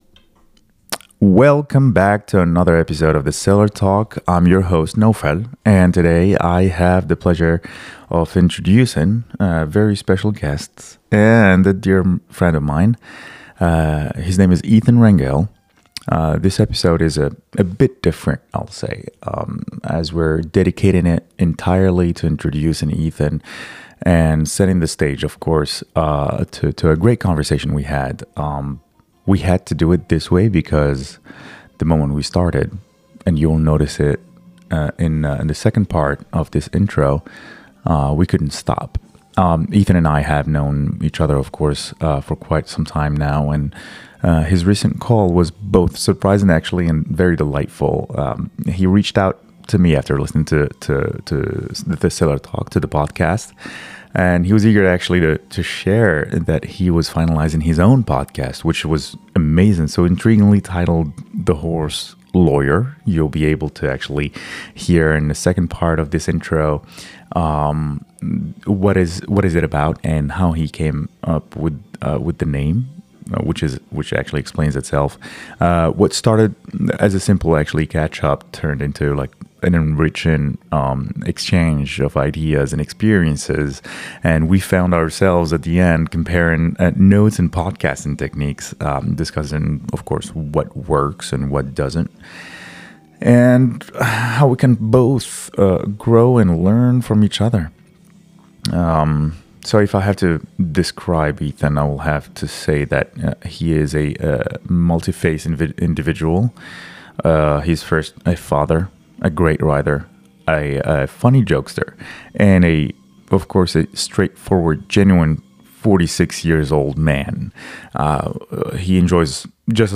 » This episode captures their engaging and unfiltered conversation as they discuss podcasting, share insights, and enjoy each other's company.